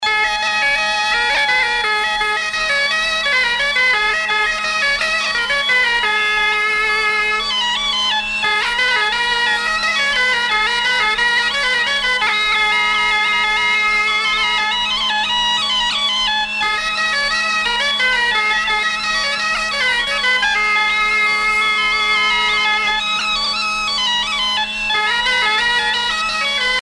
Bretagne
danse : ridée : ridée 6 temps
Pièce musicale éditée